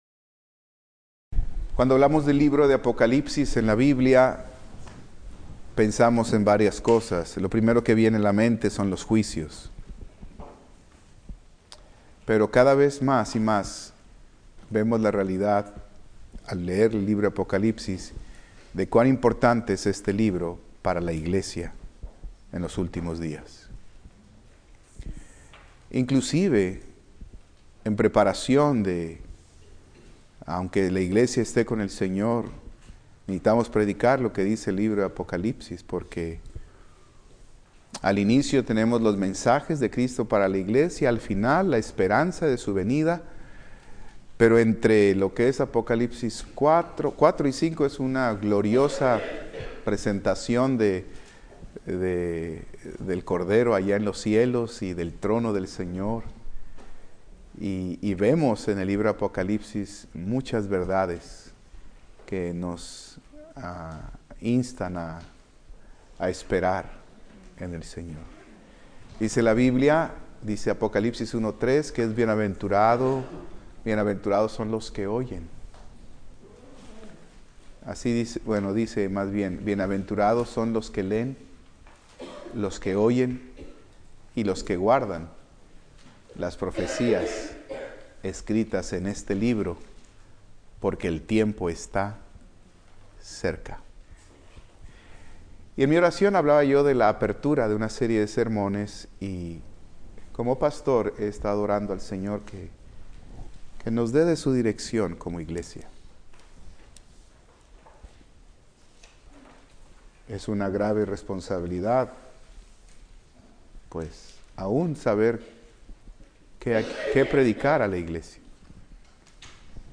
Servicio Matutino